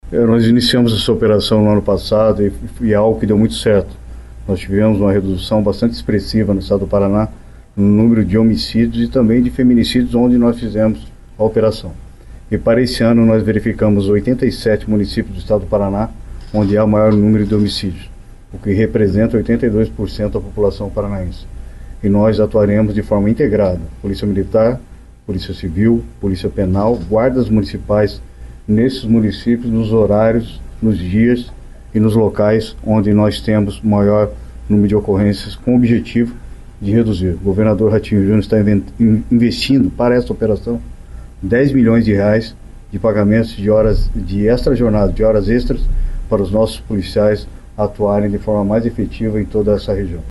O secretário de Estado de Segurança Pública do Paraná, Hudson Leôncio Teixeira, falou sobre os resultados positivos e a ampliação dos dois projetos para este ano.